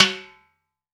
Index of /90_sSampleCDs/AKAI S6000 CD-ROM - Volume 5/Cuba2/TIMBALES_2